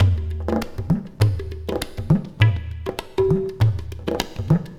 It features a mixture of field and studio recordings and programming for
Tabla,
Indian Flute,
Talking Drums,
Oriental Percussion,